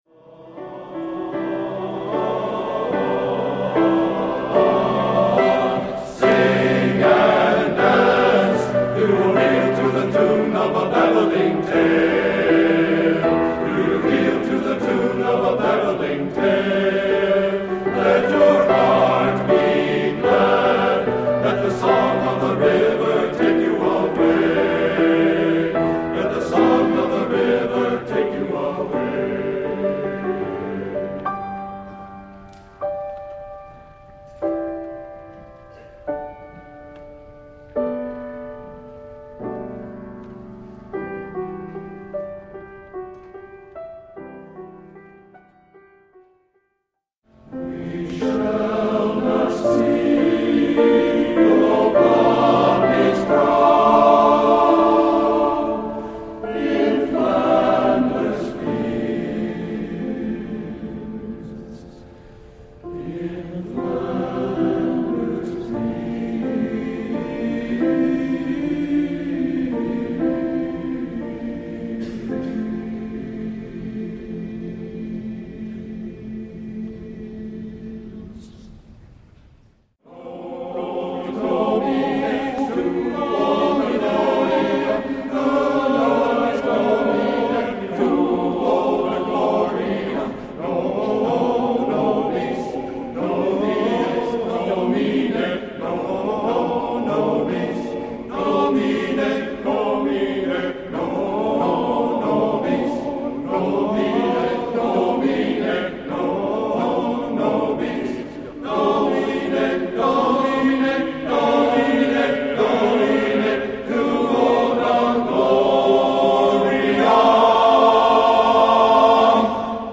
male chorus music
Excerpts of our April 29, 2007 concert at Kalamazoo Valley Community College.